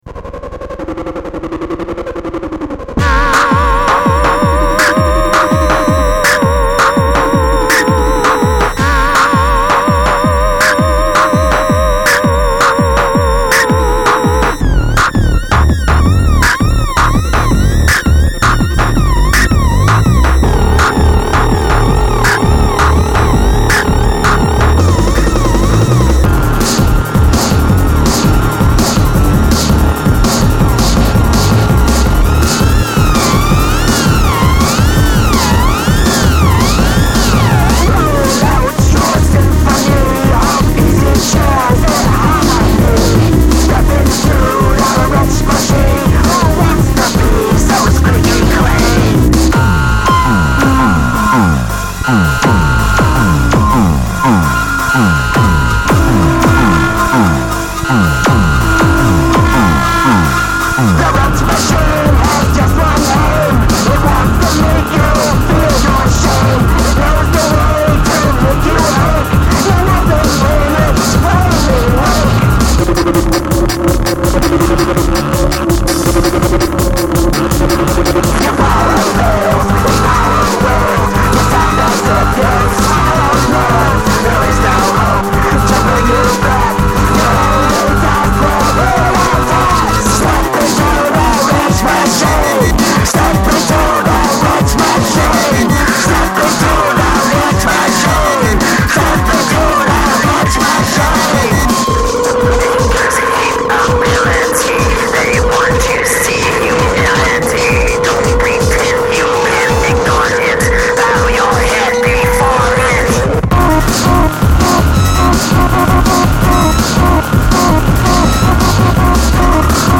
デモ・ソング（直リンクです。ボリューム注意）